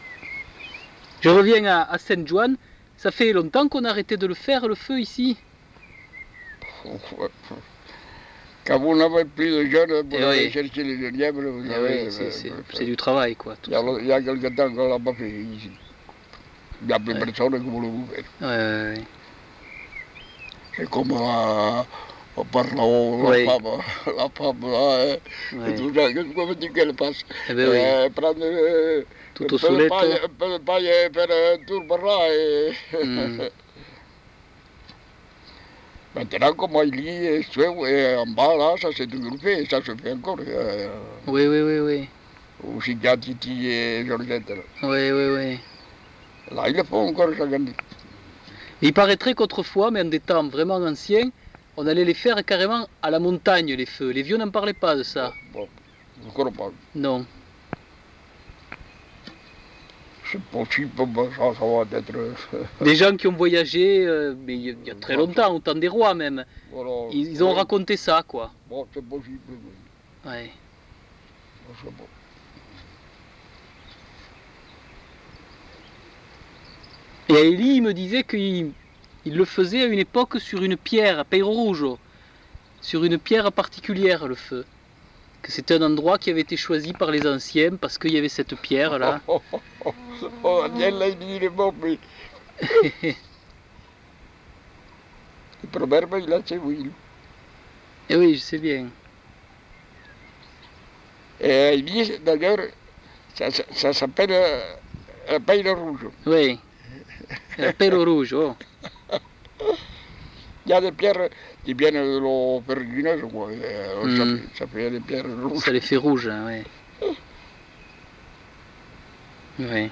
Aire culturelle : Couserans
Genre : témoignage thématique